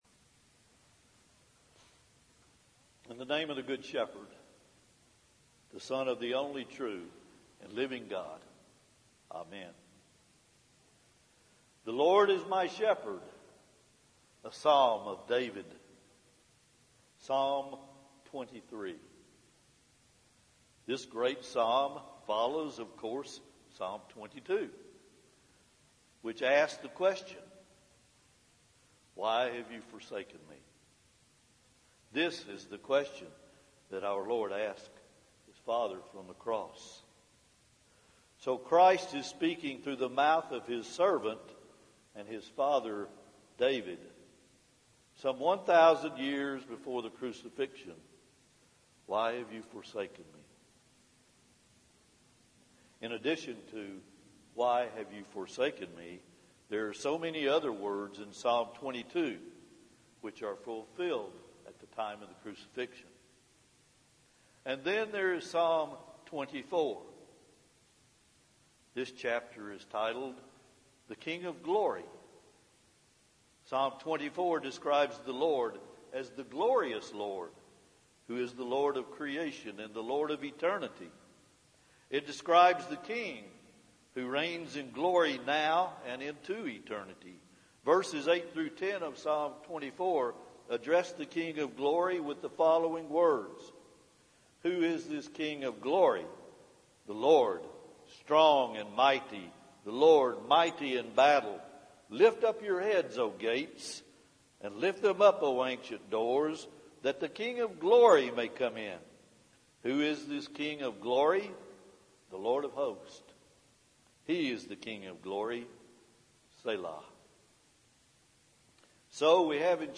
Psalms Psalm 22 – Psalm 24 Audio Sermon http